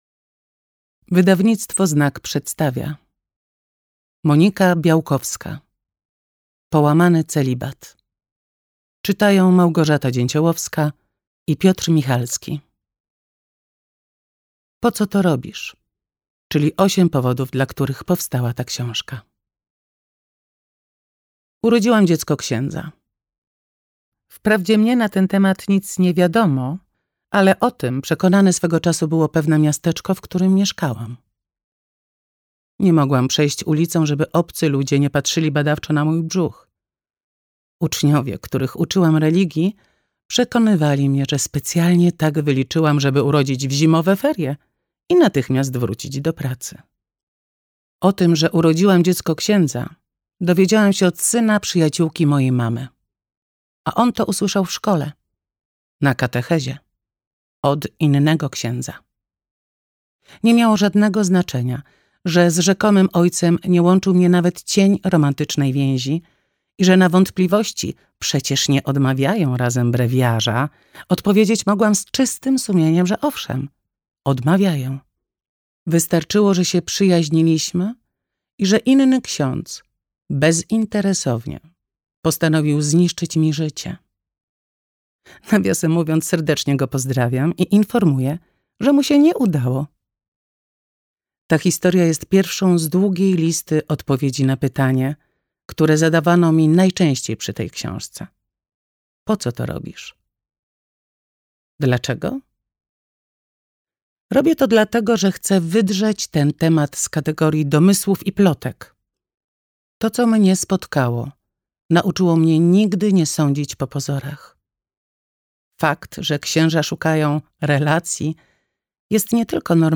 Połamany celibat - Białkowska Monika - audiobook